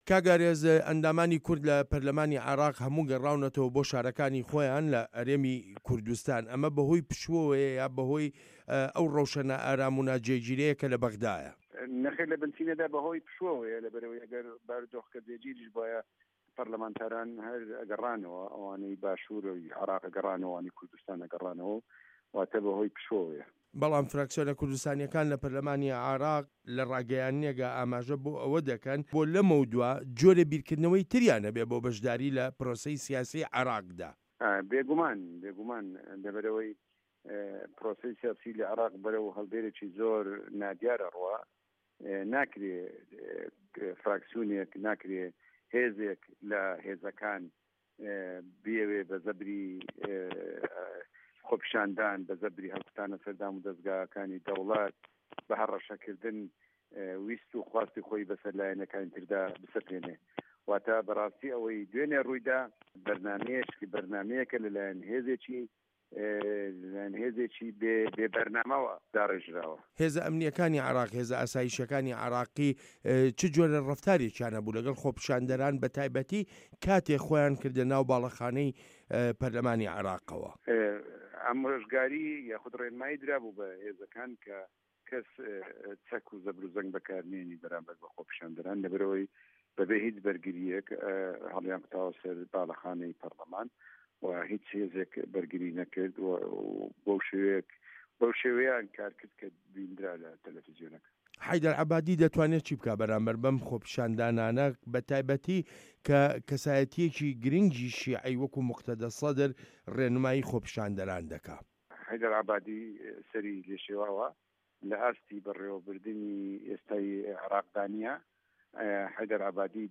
وتووێژ لەگەڵ ئارێز عەبدوڵا